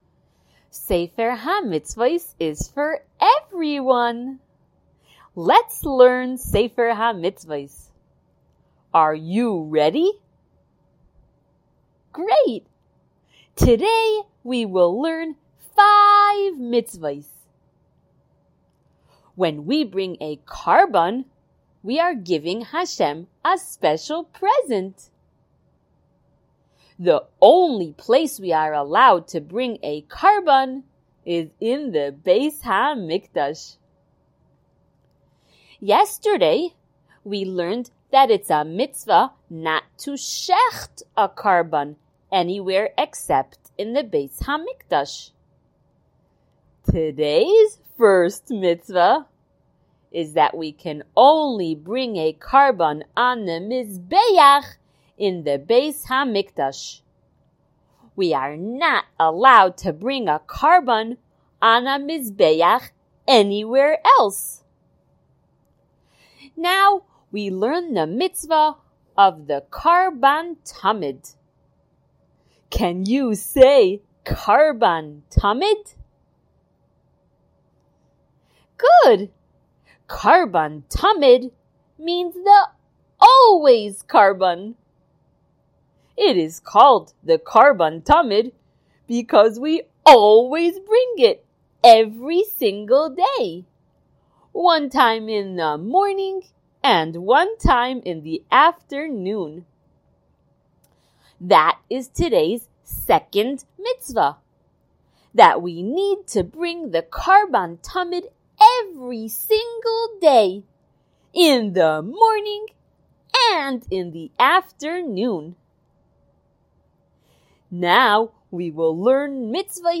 SmallChildren_Shiur165.mp3